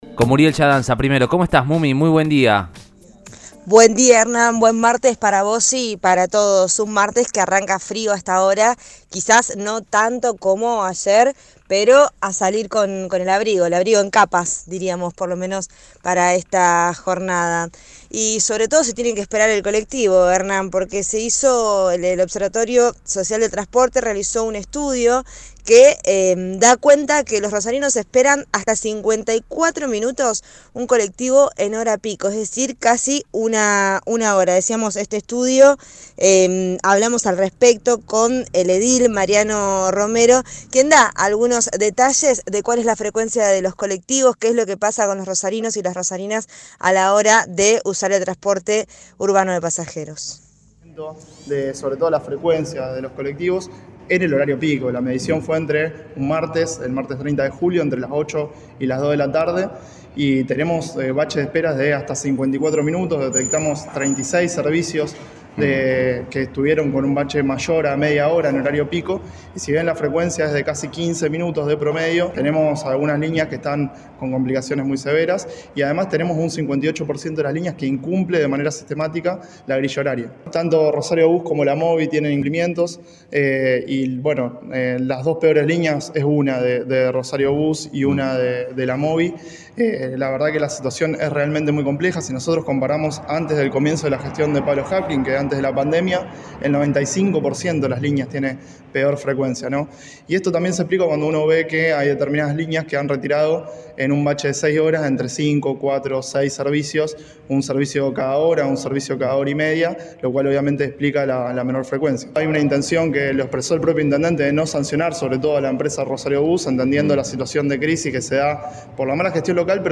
El edil peronista Mariano Romero habló con el móvil de Cadena 3 Rosario, en Radioinforme 3, y detalló que la medición se realizó el 30 de julio, entre las 8 y las 14, y se detectaron “36 servicios que estuvieron con un bache mayor a media hora en horario pico”.